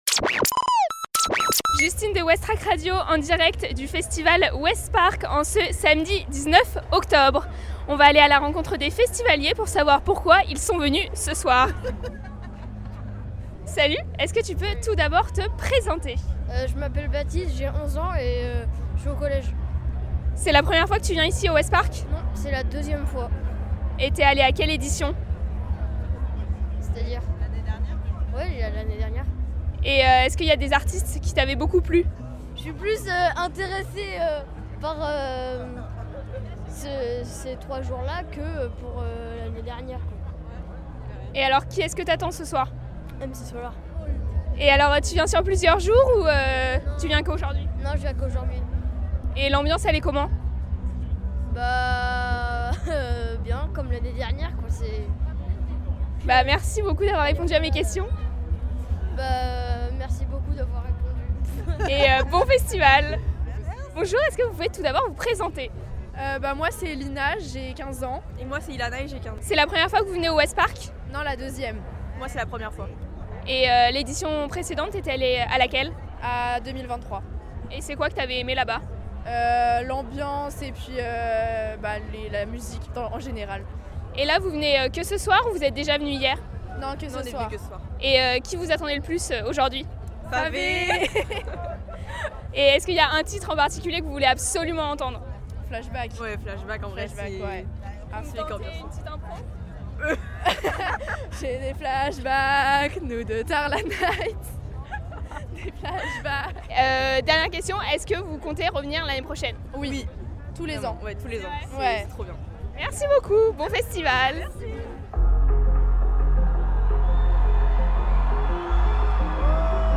Ouest Park Festival 2024 : Jour 2 Micro-trottoir
Le Ouest Park Festival 2024, c'était du 16 au 20 Octobre au Fort de Tourneville, Le Havre.